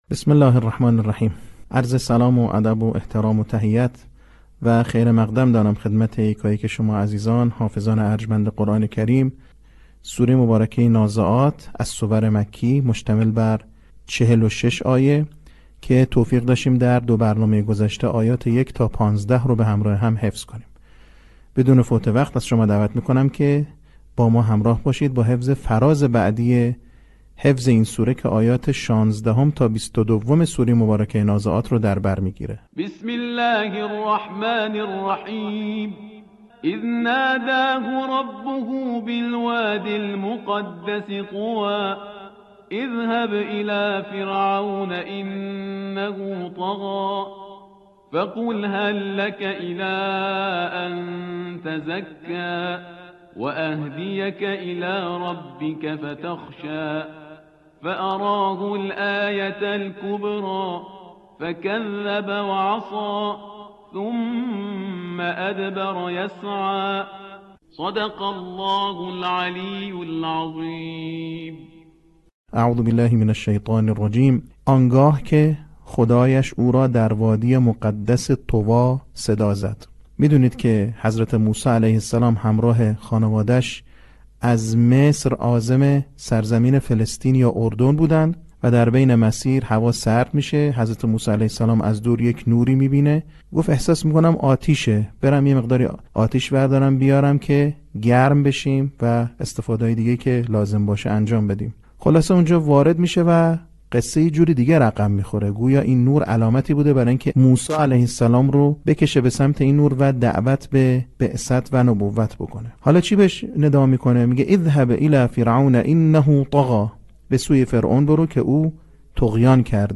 صوت | بخش سوم آموزش حفظ سوره نازعات
به همین منظور مجموعه آموزشی شنیداری (صوتی) قرآنی را گردآوری و برای علاقه‌مندان بازنشر می‌کند.